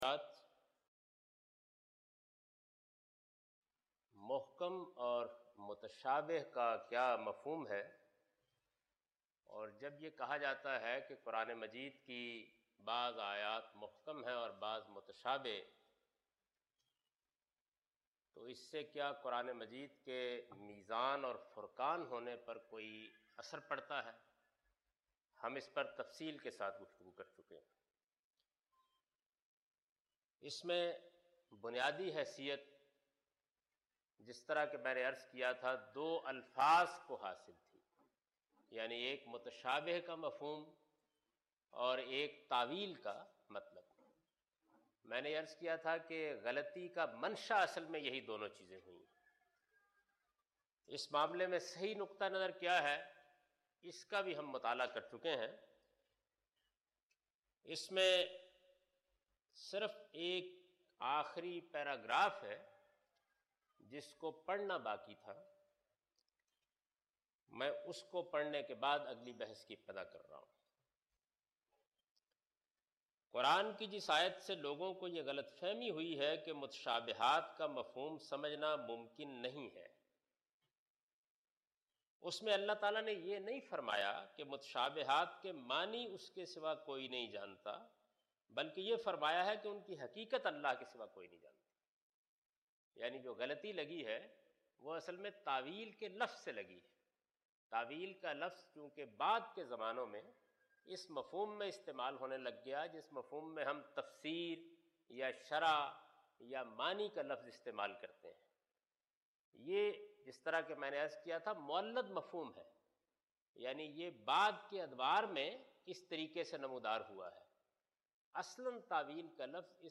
In this lecture he teaches the intentionality of the text Muhkam and Mutashabih in Quran. (Lecture no.31 – Recorded on 3rd May 2002)